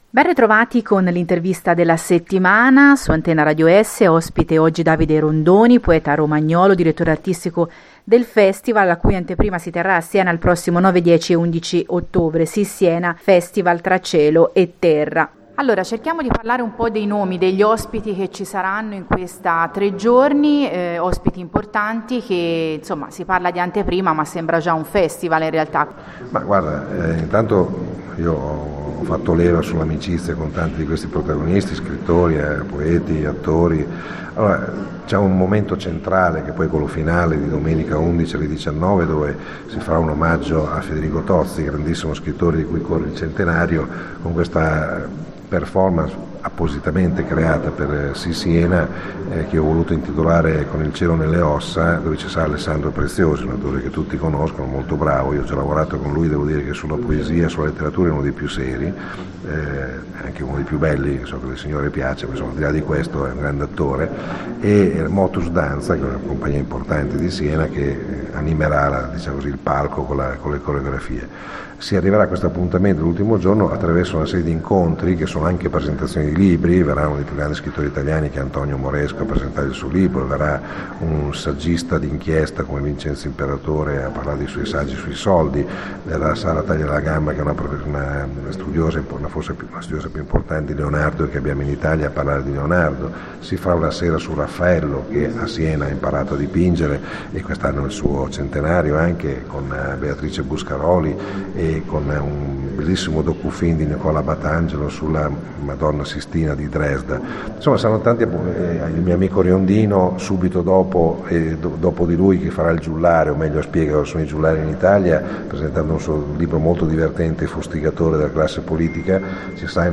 L’intervista della settimana – Ospite il poeta romagnolo Davide Rondoni